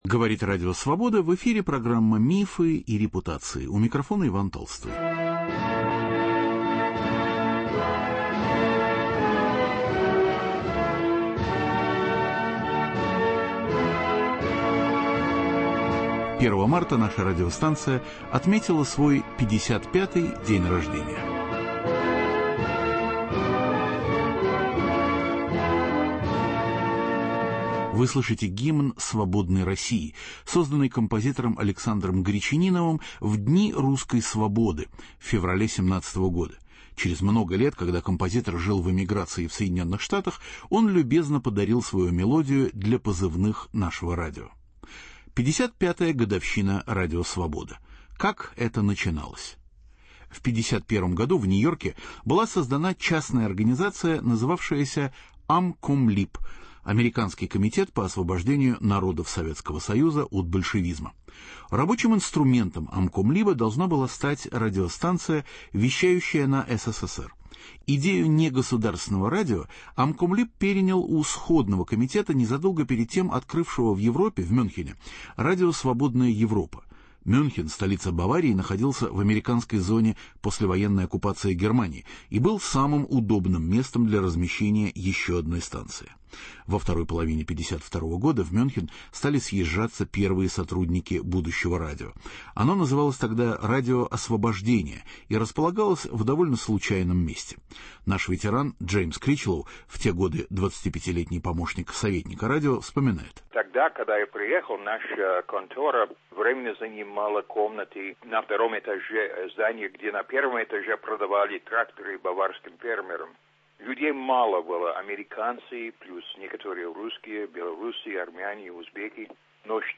История первых лет, голоса прошлого, сегодняшние интервью. Ответы на вопросы, которые чаще всего задают наши слушатели.